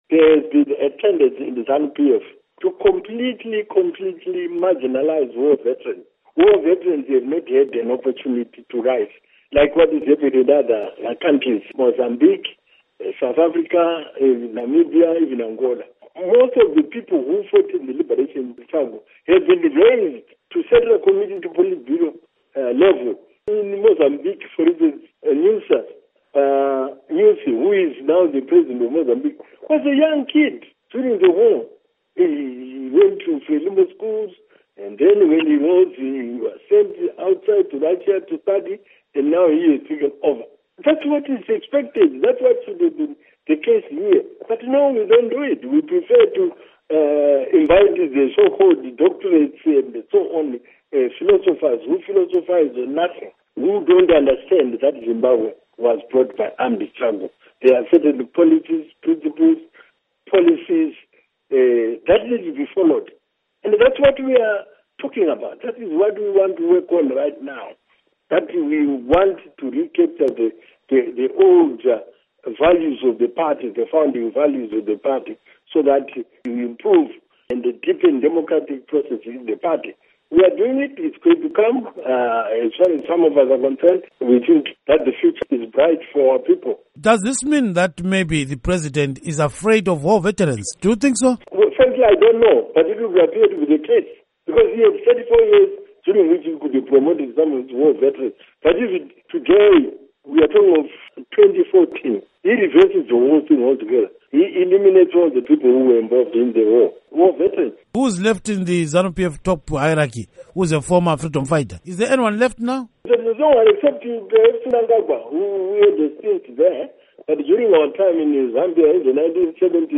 Gumbo told Studio 7 in an interview that indications are that Mr. Mugabe, who has been in power since independence from British rule in 1980, fears war veterans.
Interview With Rugare Gumbo on Mugabe Rule